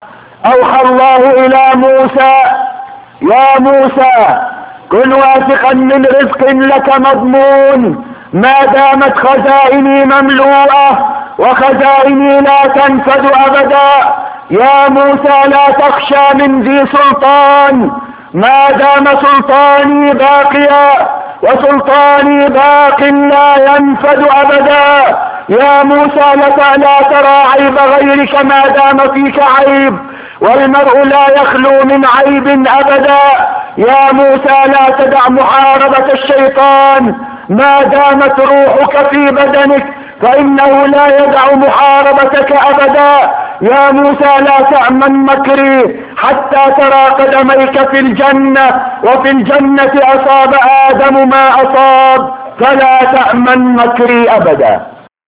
Sample of his speeches (54 seconds)